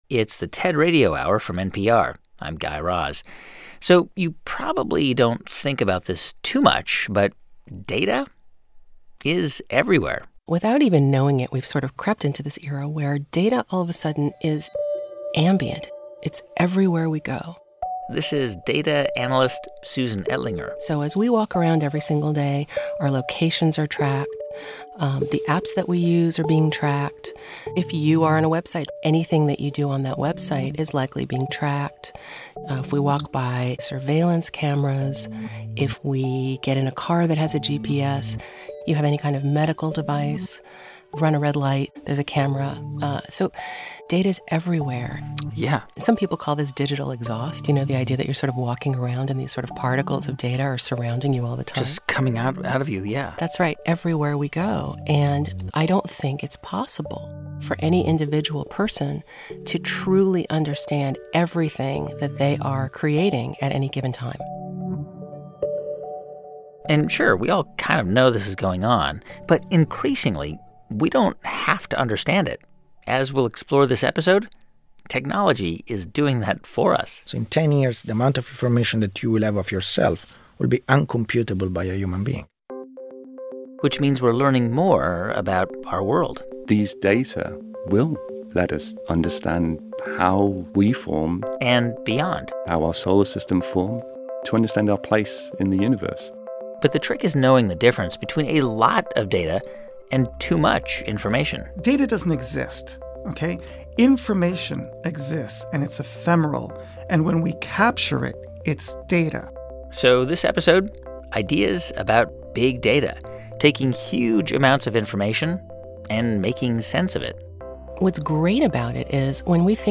This hour, TED speakers imagine how Big Data will reshape our world.